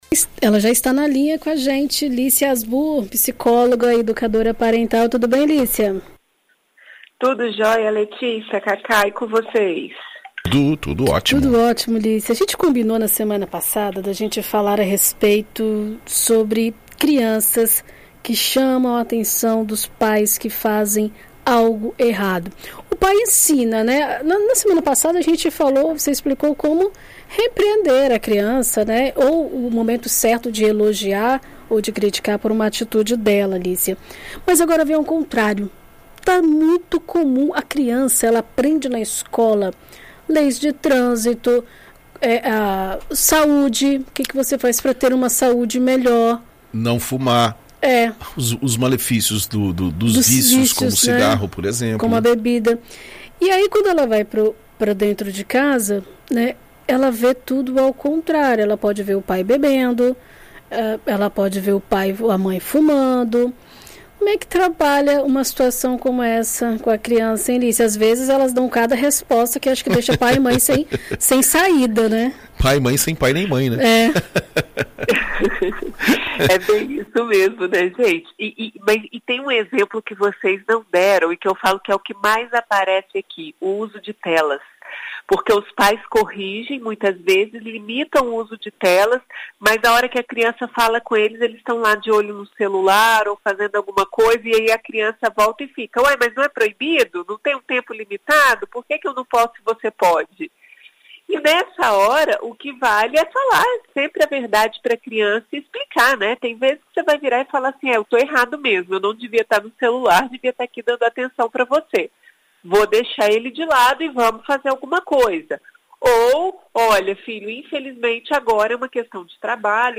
Em entrevista à BandNews FM Espírito Santo nesta segunda-feira